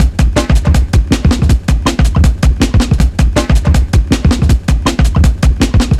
Index of /90_sSampleCDs/Zero-G - Total Drum Bass/Drumloops - 1/track 09 (160bpm)